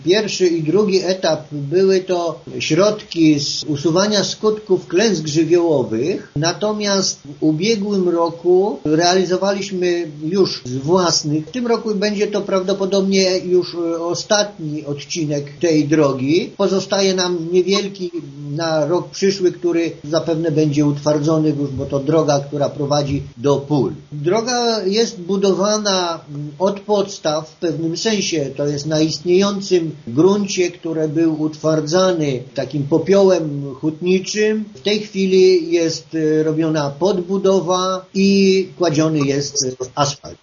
Wójt Franciszek Kwiecień przypomina, że dotychczasowe prace gmina finansowała z różnych źródeł: